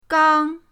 gang1.mp3